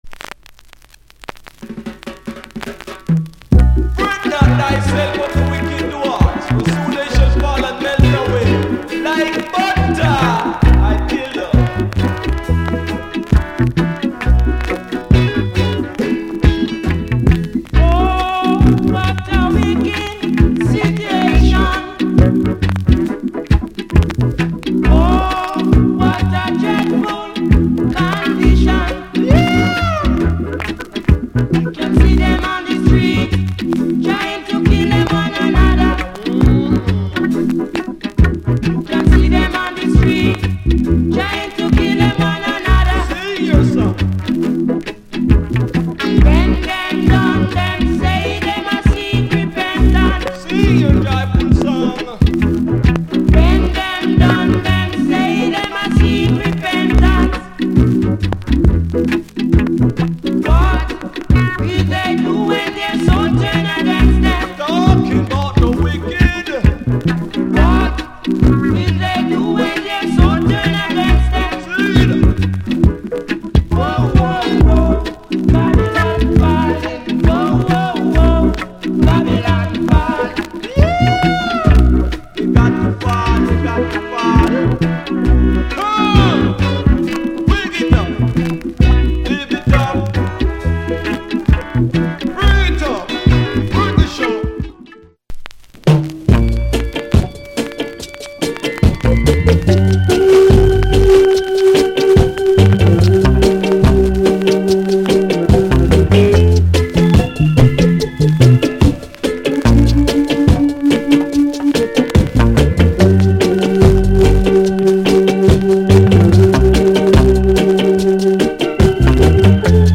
Genre Reggae70sEarly / Male Vocal Male DJ